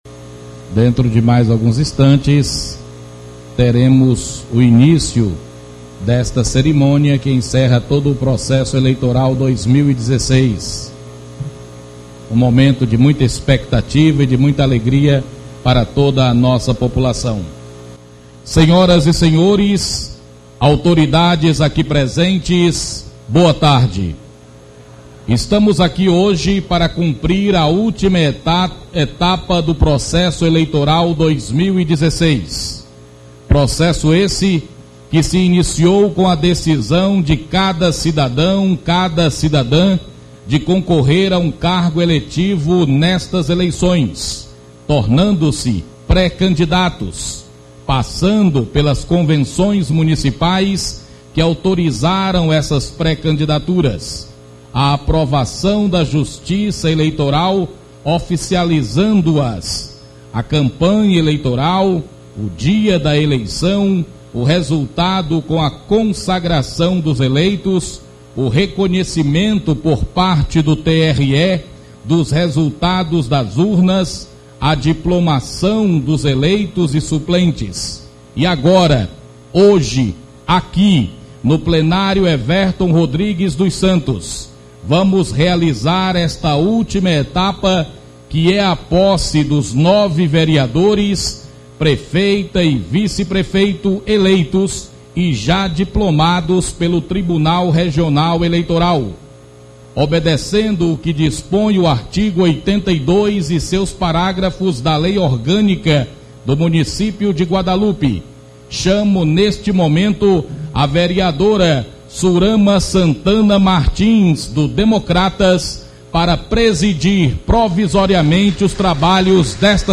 Áudio das Sessões do ano de 2017